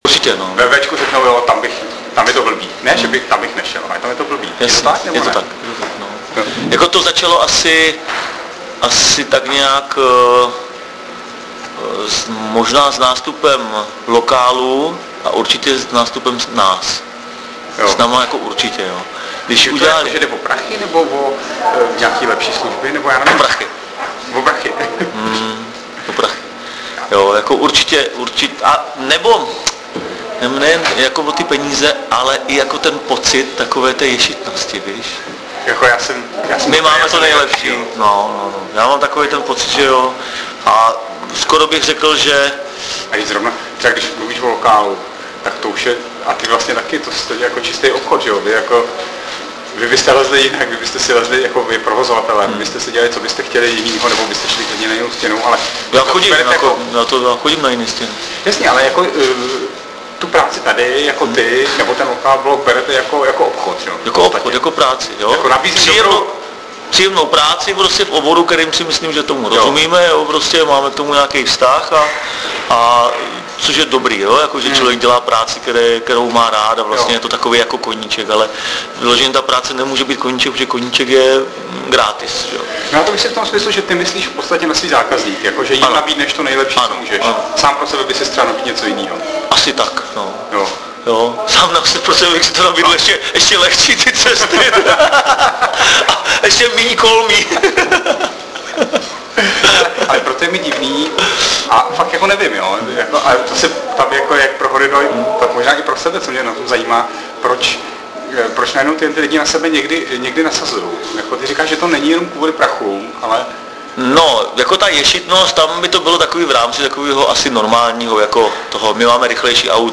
AUDIO: Rozhovor